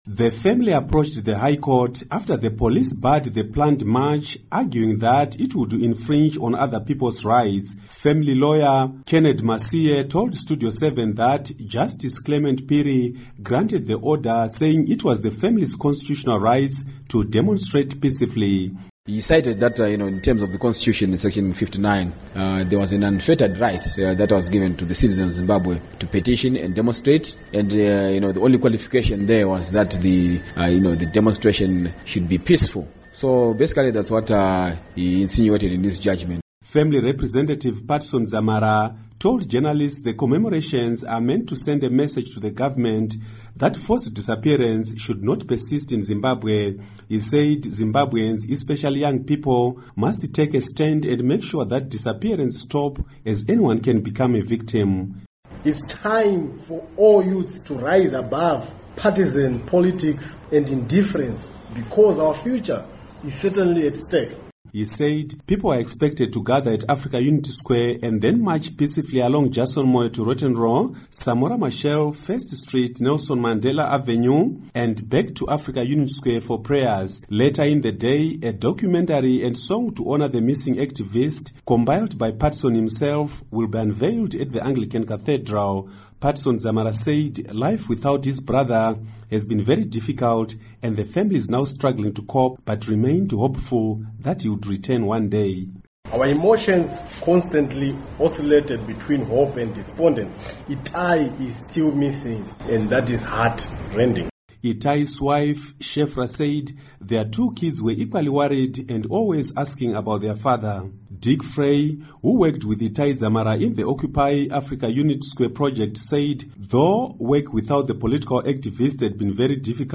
Report on Itai Dzamara Public March